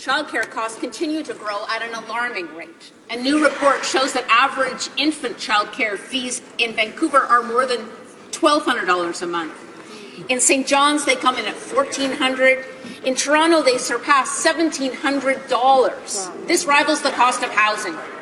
In the House of Commons, MP for Ladysmith Nanaimo, Sheila Malcolmson says the Liberals have failed to produce the childcare spaces they promised and the cost of childcare rivals the cost of housing…..